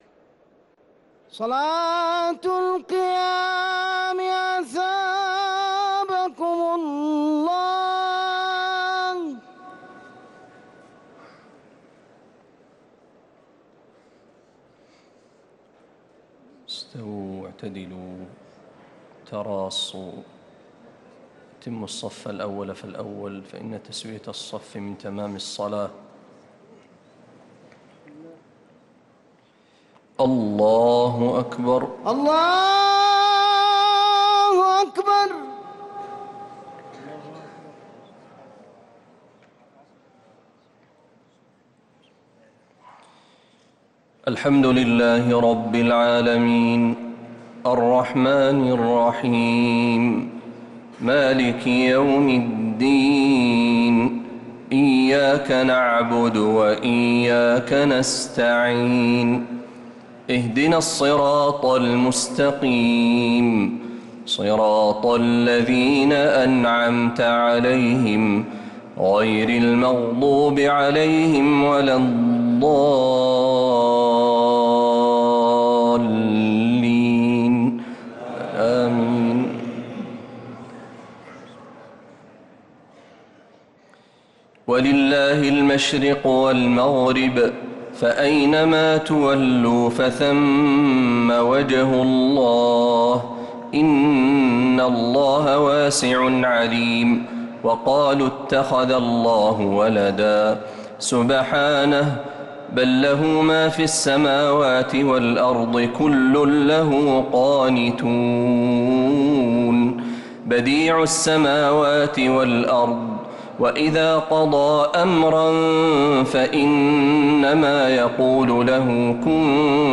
صلاة التراويح